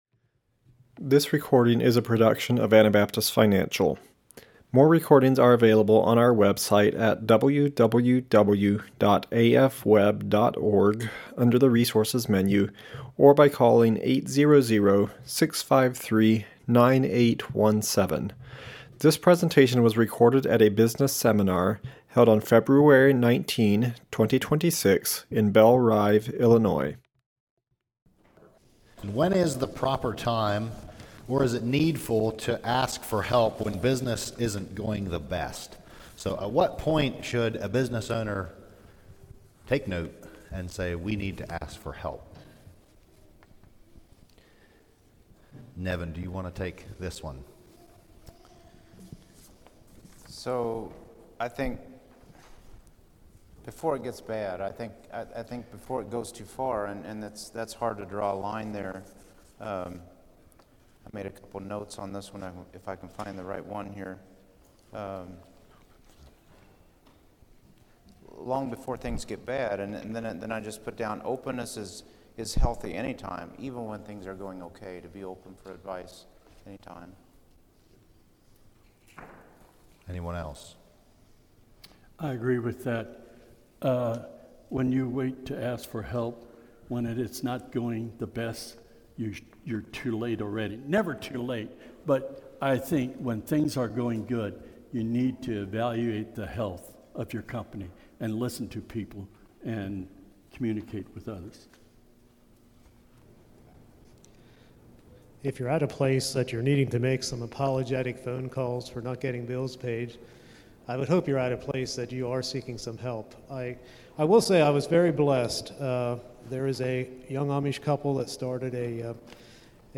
Business Management Panel - Anabaptist Financial
The seminar speakers represent a wealth of experience in business management and leadership.